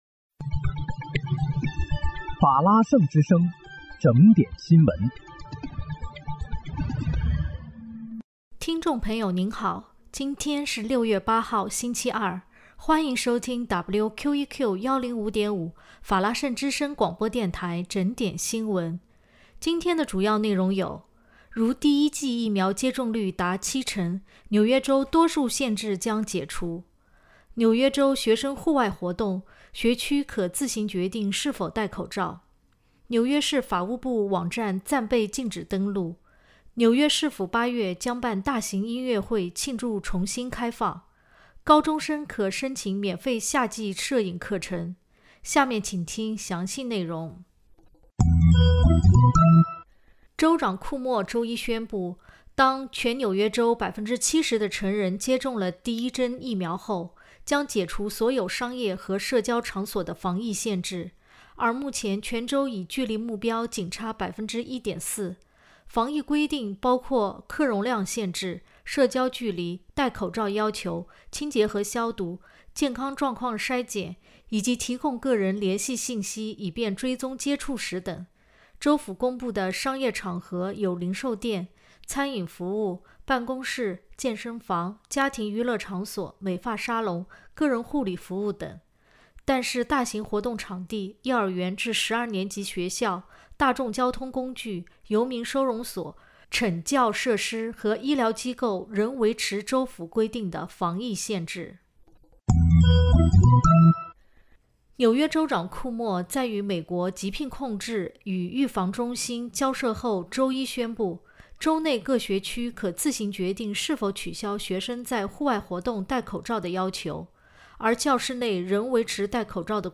6月8日（星期二）纽约整点新闻